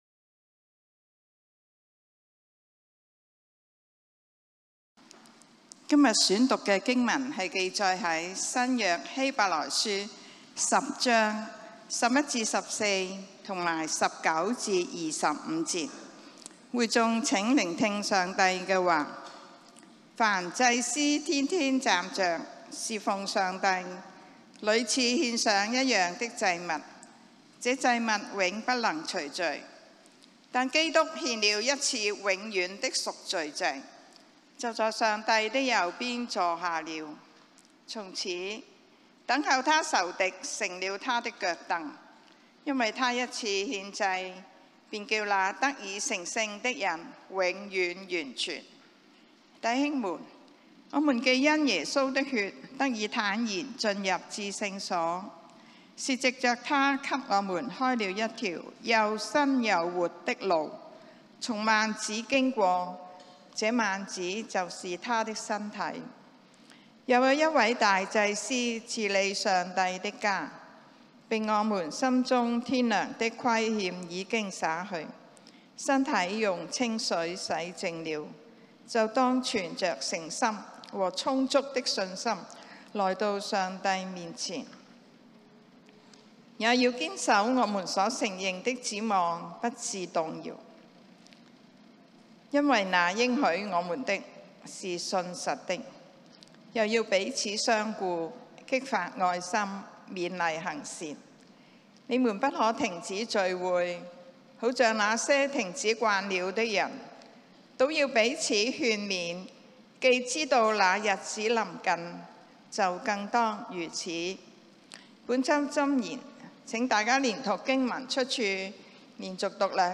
講道經文：《希伯來書》 Hebrews 10:11-14; 19-25